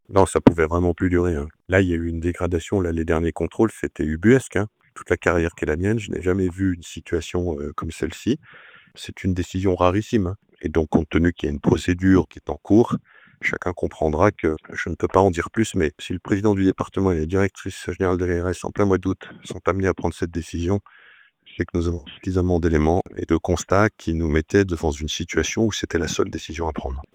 ITC Martial Saddier 2-Fermeture Ehpad Val Montjoie St Gervais